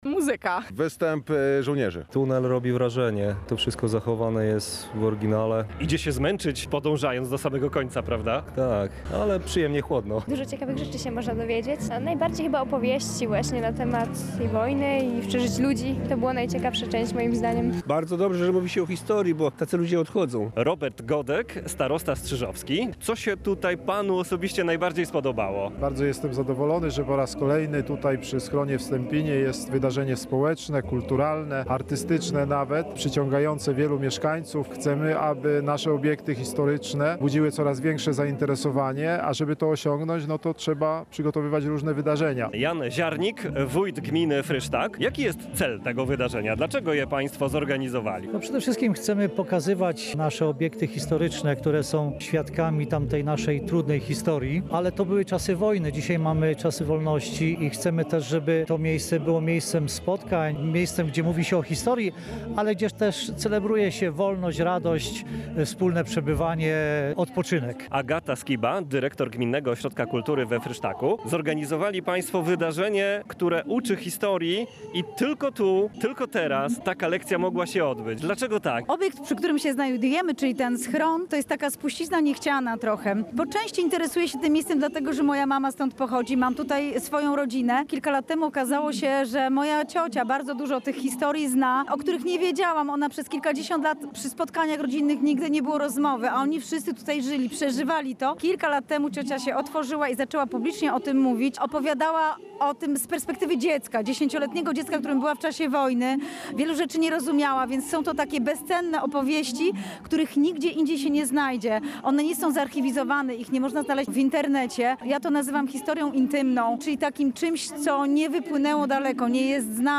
Zapytaliśmy zwiedzających, co najbardziej przypadło im do gustu – wśród odpowiedzi dominowały zachwyty nad samym schronem, ale także atmosfera wydarzenia.